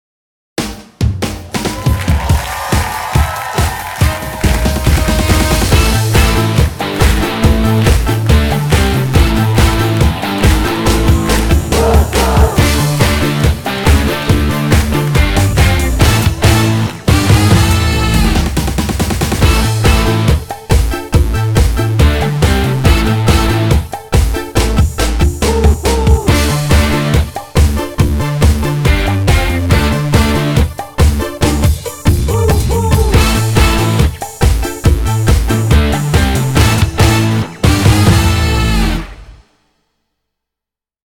Générique de?